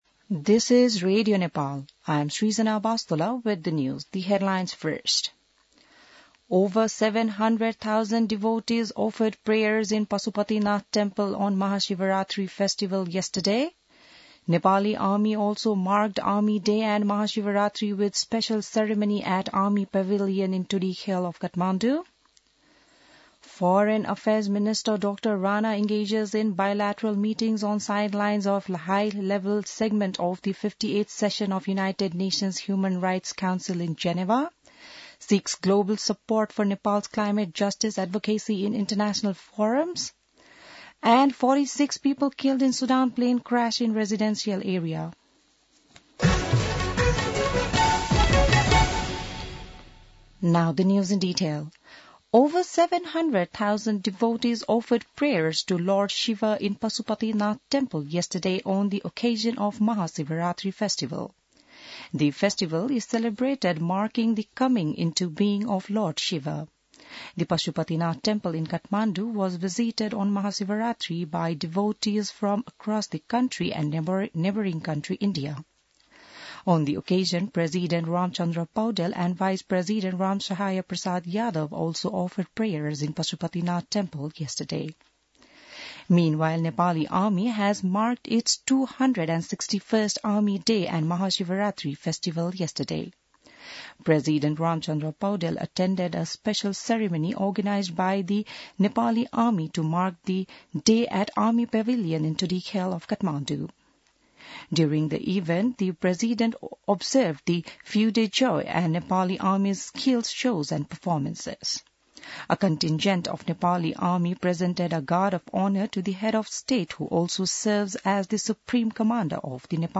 बिहान ८ बजेको अङ्ग्रेजी समाचार : १६ फागुन , २०८१